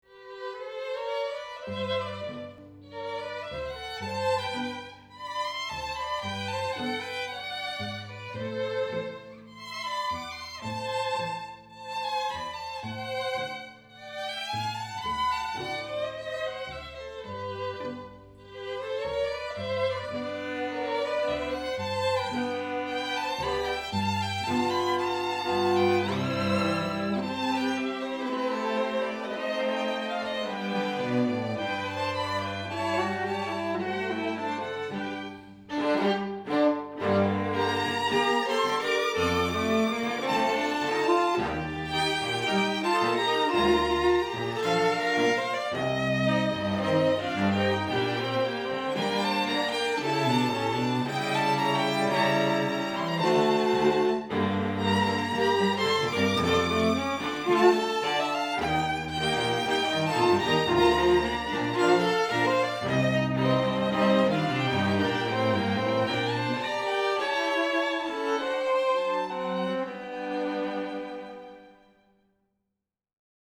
Quatuor
Tango